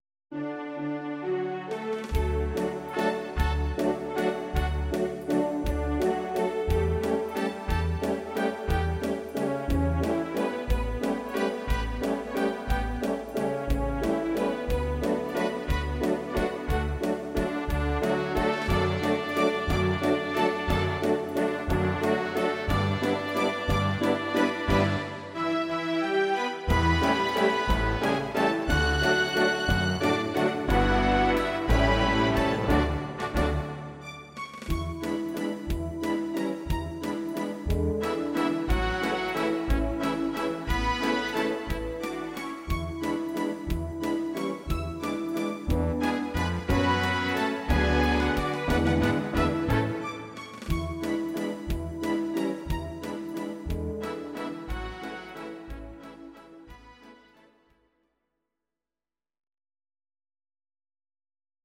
These are MP3 versions of our MIDI file catalogue.
Please note: no vocals and no karaoke included.
Your-Mix: Instrumental (2073)